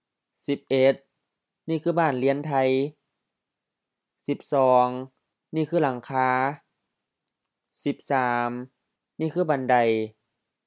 บ้านเลียน ba:n-li:an HF-HR บ้านเรือน house, home
หลังคา laŋ-ka: M-HR หลังคา roof
บันได ban-dai M-M บันได stairs, staircase, ladder